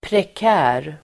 Ladda ner uttalet
prekär adjektiv, precarious Uttal: [prek'ä:r]